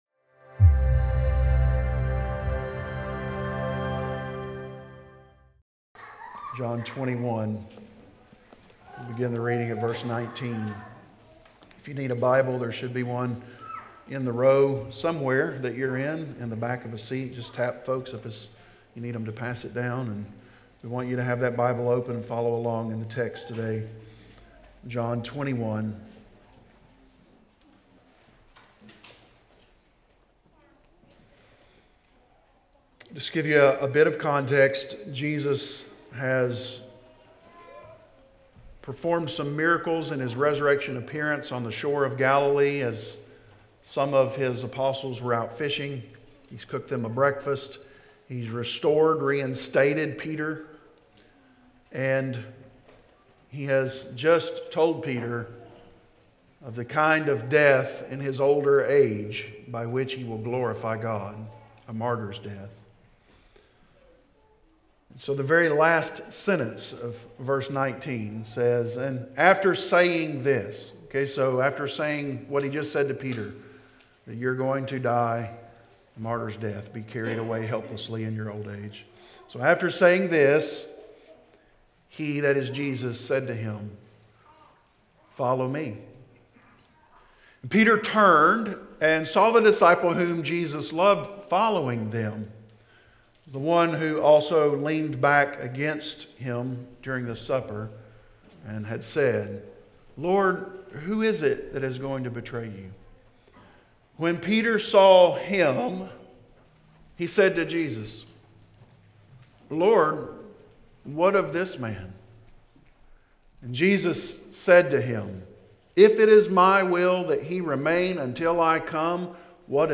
preaches through Malachi